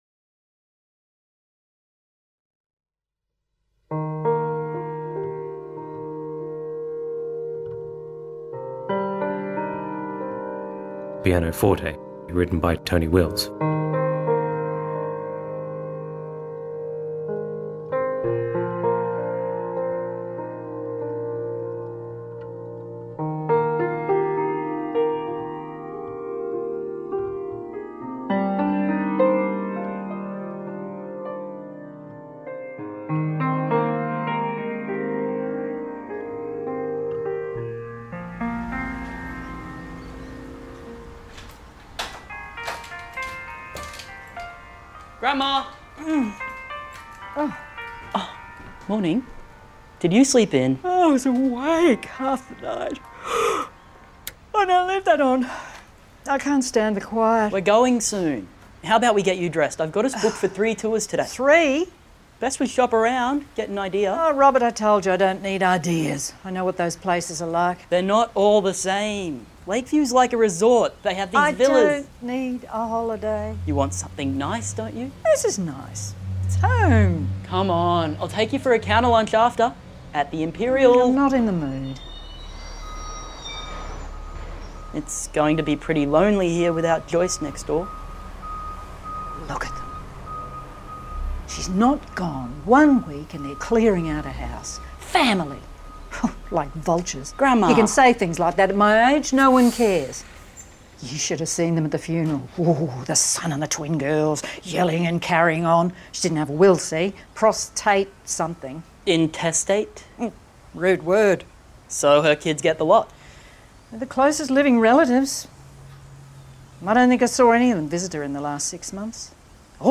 This is a an audio drama commissioned by the Suncoast Community Legal Service as a part of their Elder abuse campaign.
The concept of the production for me was to facilitate the recording of the performance artists in a way that would allow the artists to perform freely (if need be) within the recording space by utilising television production audio recording technology, combined with radio play staging methodology.